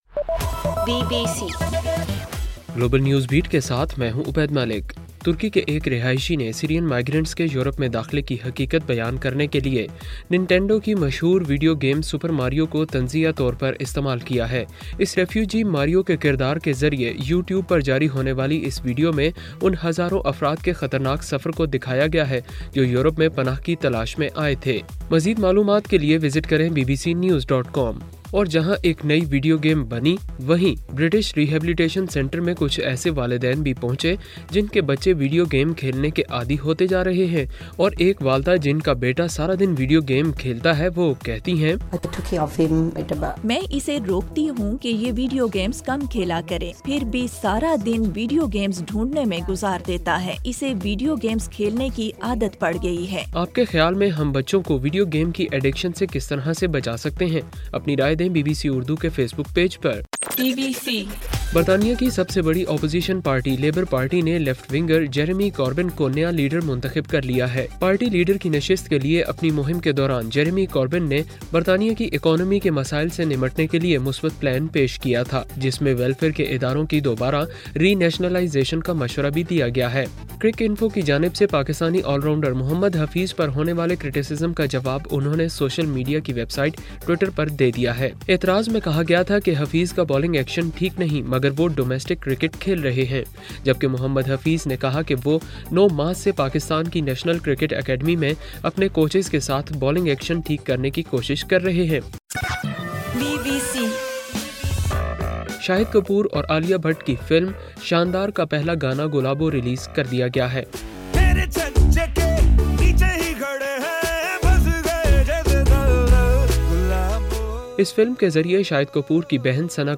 ستمبر 12: رات 10 بجے کا گلوبل نیوز بیٹ بُلیٹن